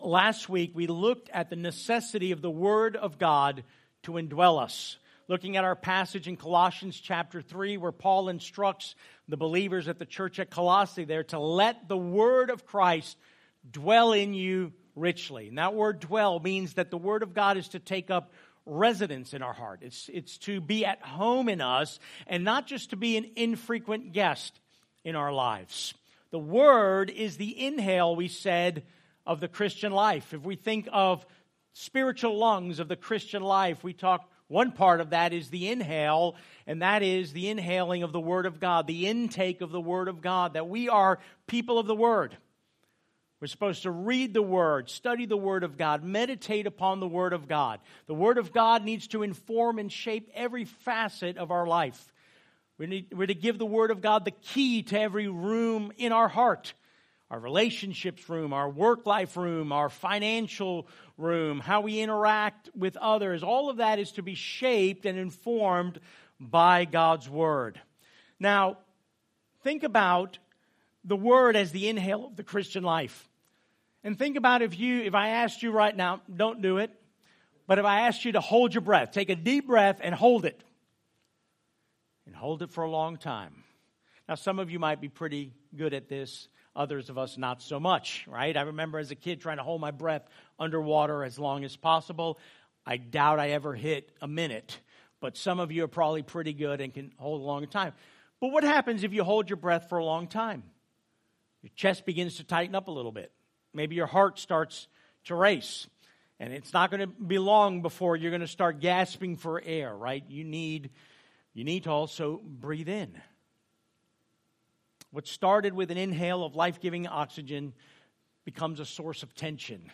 Sent Church Lake Mary, Longwood, Sanford FL | Sermons